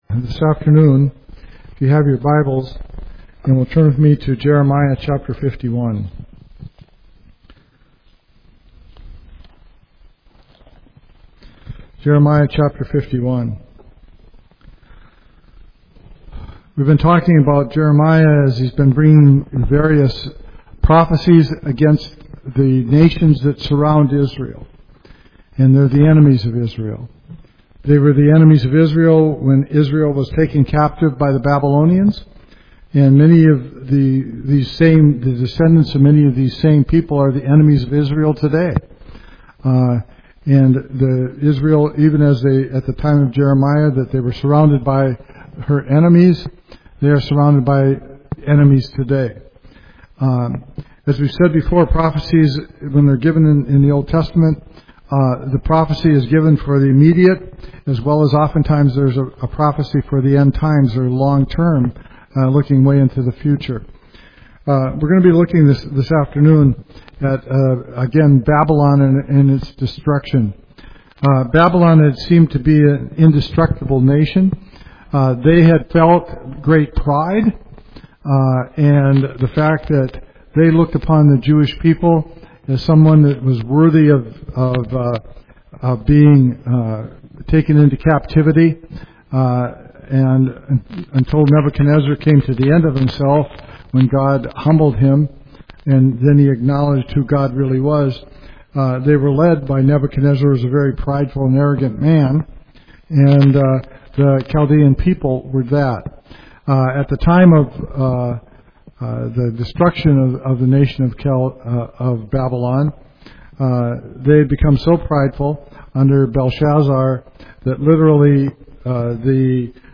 Explore Jeremiah 51 and its powerful prophecy of Babylon’s downfall. This in-depth sermon reveals the historical and spiritual meaning behind God’s judgment on a nation that defied Him—and how it speaks to our world today.